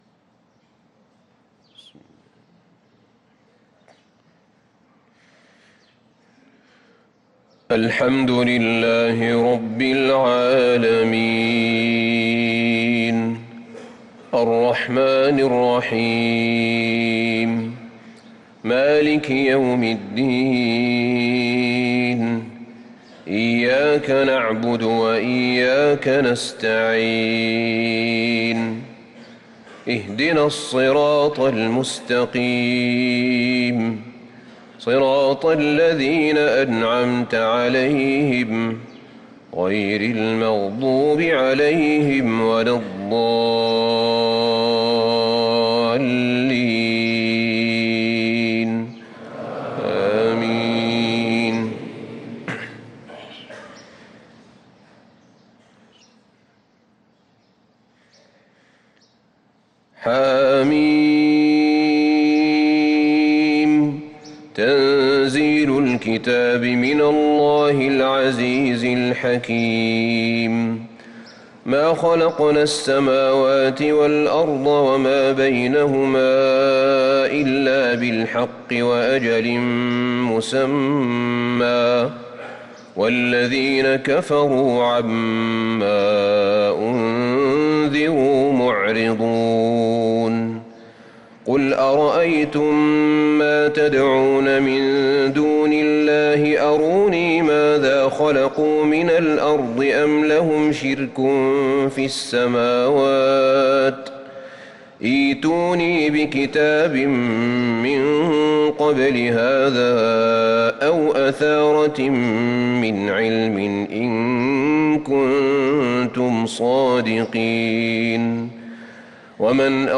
صلاة الفجر للقارئ أحمد بن طالب حميد 12 محرم 1445 هـ
تِلَاوَات الْحَرَمَيْن .